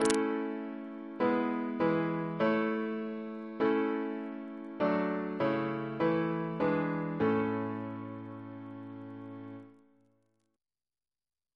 CCP: Chant sampler
Single chant in A Composer: William Turner (1651-1740) Reference psalters: OCB: 131; PP/SNCB: 61